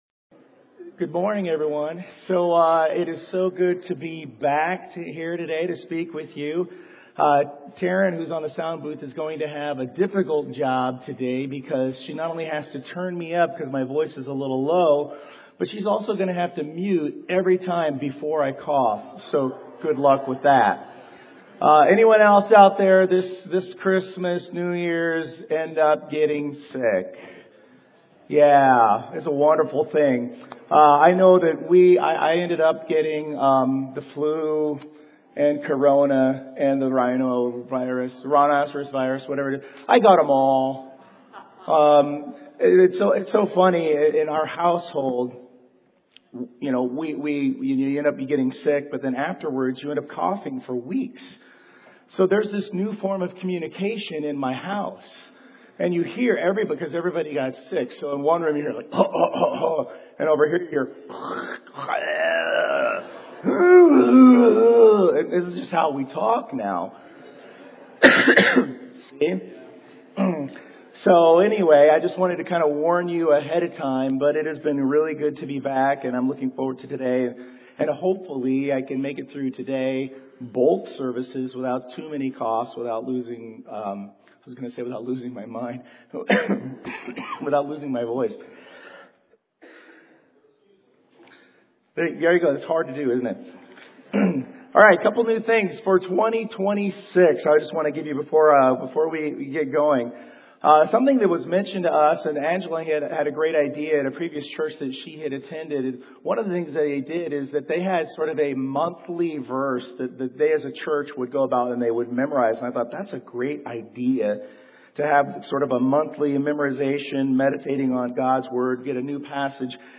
Series: 2026 Sermons